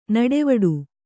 ♪ naḍevaḍu